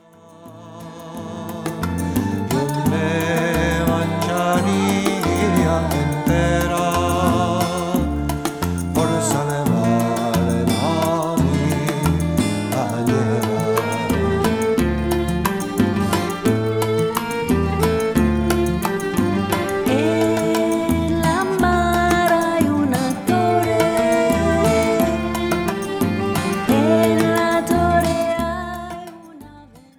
• Ladino/Sephardic